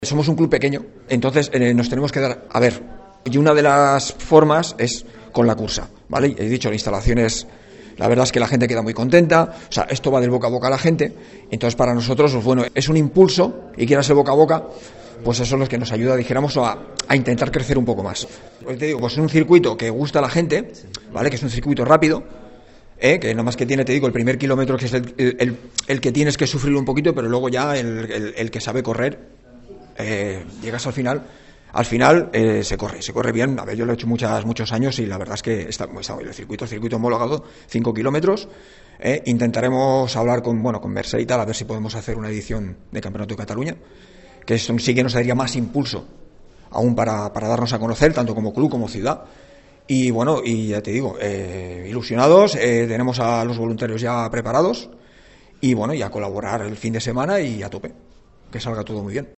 Presentada aquesta tarda a la Casa de la Vila la 26a edició de la Cursa de Martorell, una cita coorganitzada per l’Ajuntament i el Martorell Atlètic Club (MAC).